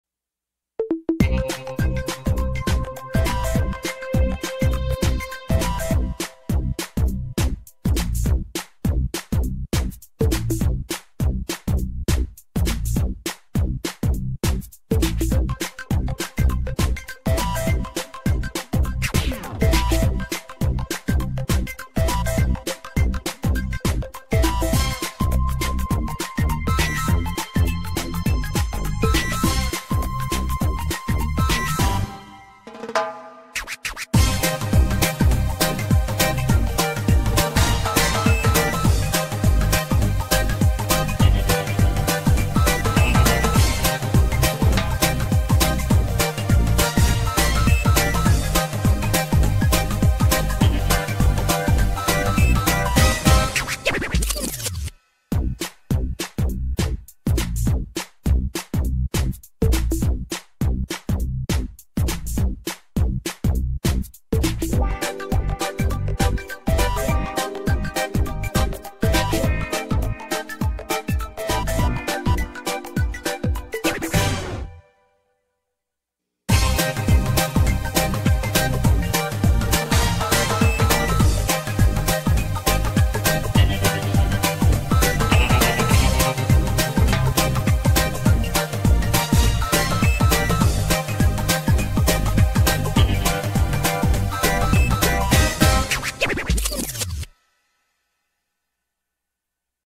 Busted (Instrumental).mp3